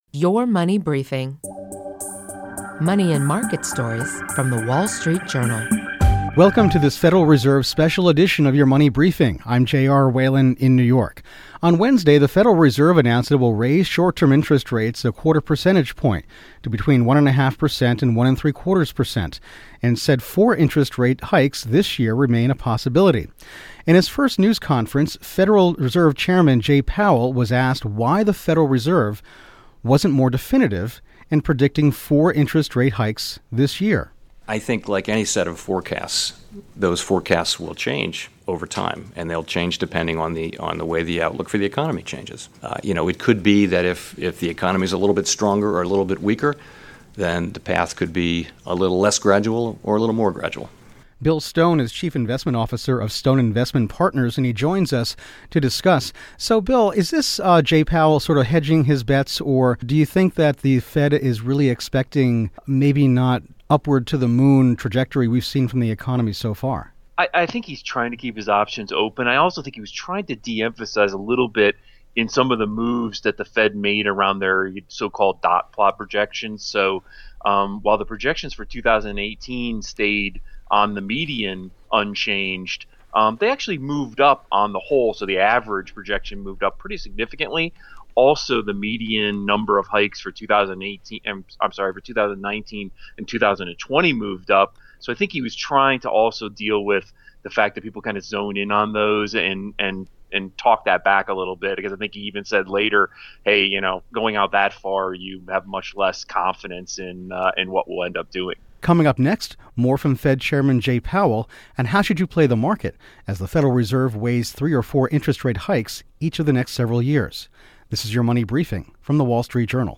Special Fed Coverage: Powell Press Conference